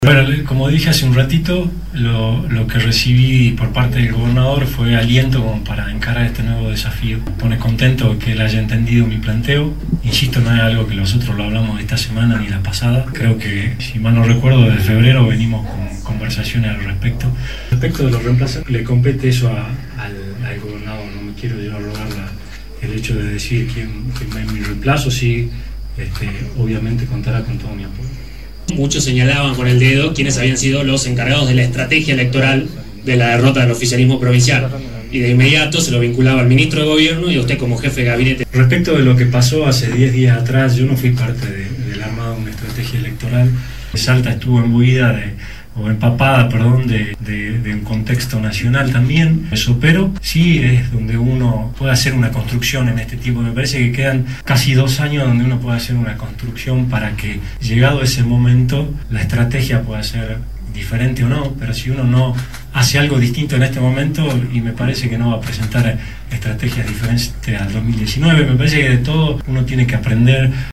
El saliente jefe de gabinete de ministros, Carlos Parodi, analizó su salida del gobierno en rueda de prensa.
EL-SALIENTE-JEFE-DE-GABINETE-DE-MINISTROS-CARLOS-PARODI-ANALIZO-SU-SALIDA-DEL-GOBIERNO-EN-RUDA-DE-PRENSA.mp3